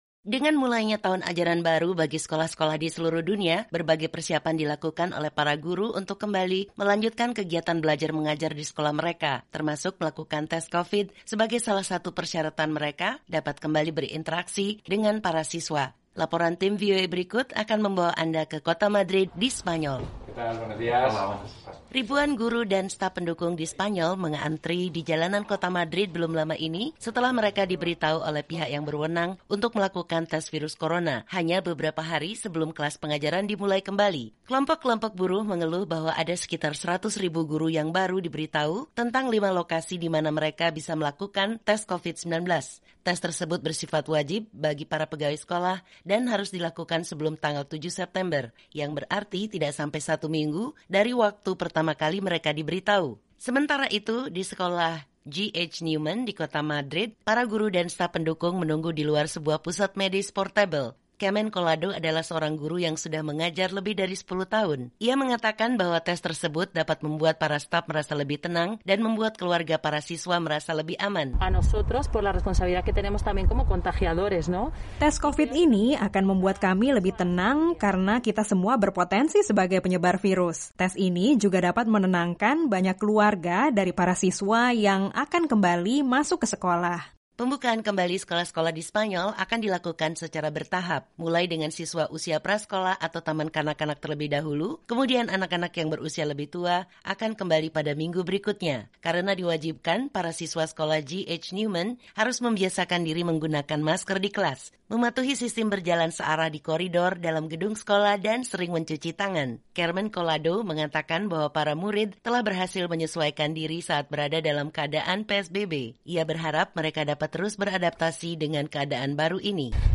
Dengan mulainya tahun ajaran baru, para guru di Spanyol melakukan sejumlah persiapan untuk kembali melanjutkan kegiatan pendidikan di sekolah mereka, termasuk melakukan test covid yang merupakan persyaratan agar dapat kembali berinteraksi dengan para siswa. Simak laporan dari kota Madrid berikut ini